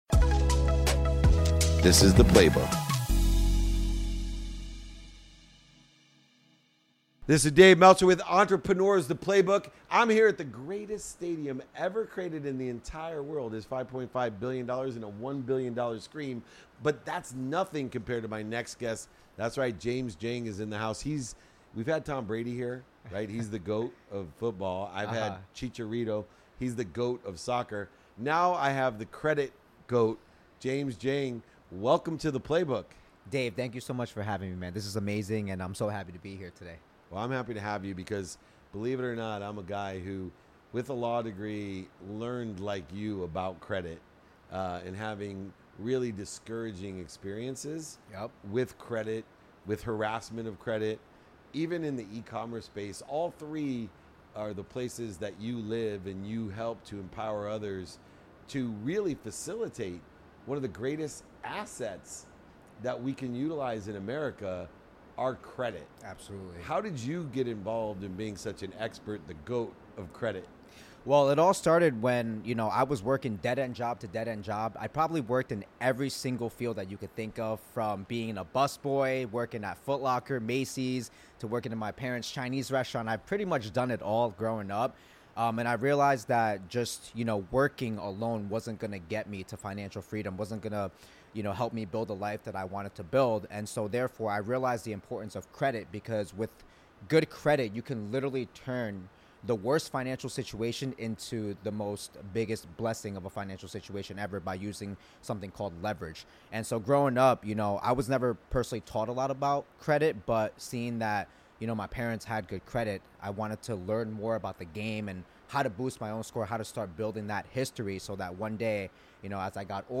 Today's episode is from a conversation